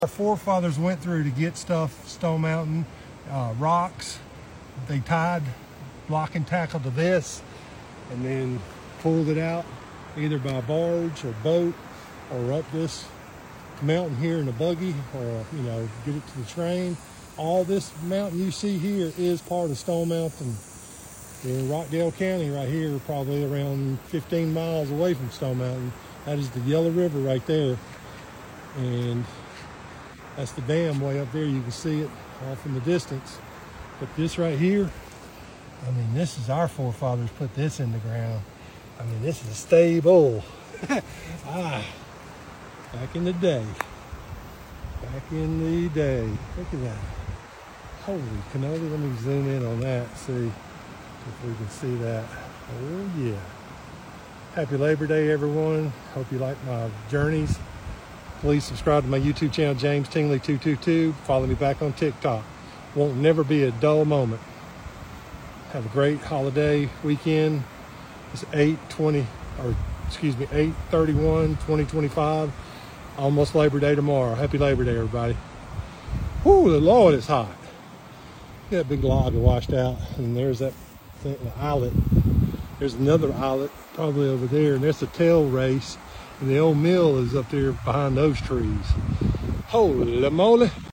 Steel eyelet in solid granite part of Stone Mountain down in Millstead Georgia on the yellow river day before Labor Day happy Labor Day everybody hope you like my journeys.